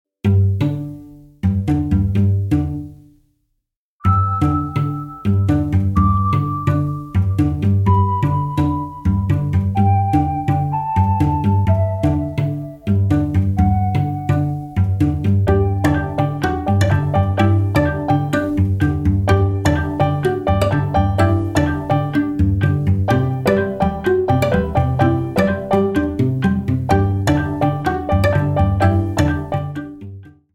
• Качество: 128, Stereo
саундтреки
спокойные
без слов
клавишные
пианино
музыка из игр